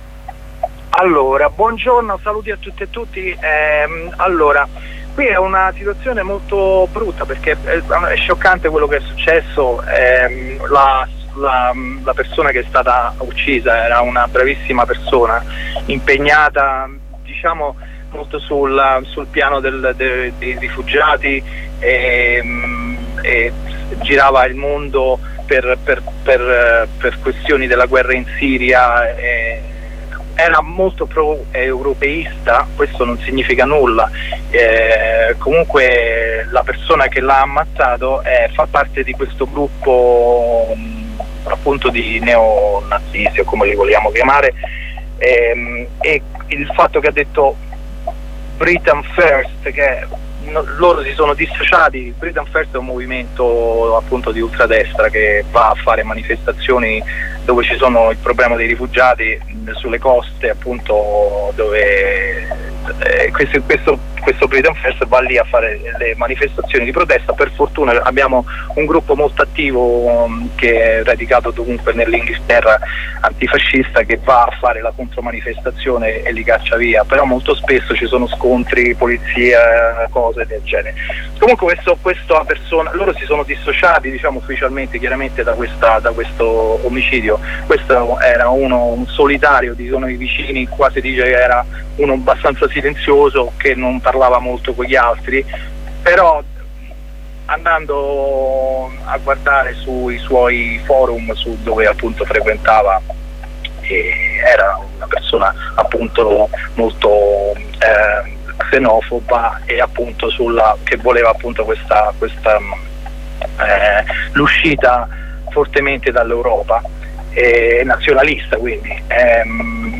Due compagni italiani, da tempo residenti nel Regno Unito, cercano di raccontarci l'aria che tira